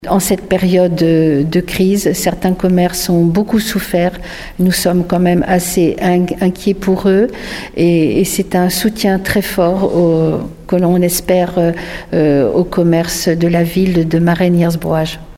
Une opération soutenue donc par la municipalité. On écoute Claude Balloteau, maire de Marennes-Hiers-Brouage :